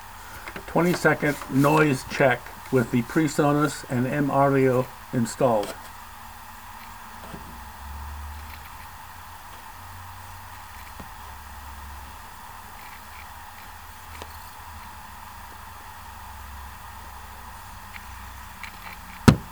Constant static/line noise
To me they both have that noise/static.
On that you are accidentally recording from the computer’s built-in microphone which picks up the sound of the hard-drive clicking.
Yes, that and the recording sounds like a good day in a boiler factory which is also typical of a built-in microphone.